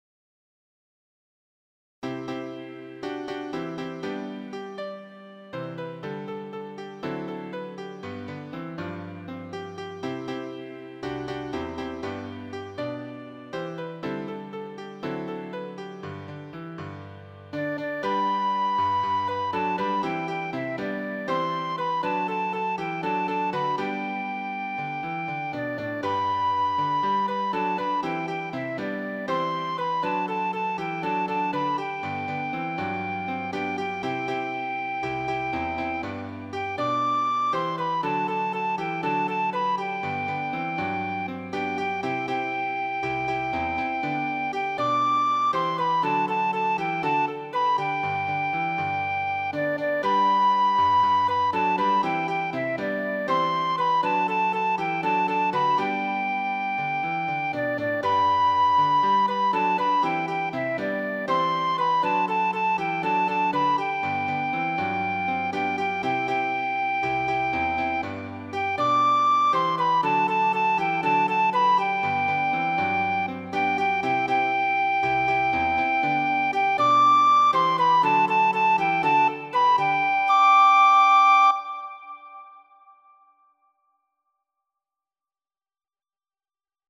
“Vamos a cantar a dos voces con los Zulúes de Sudáfrica„
1ª Voz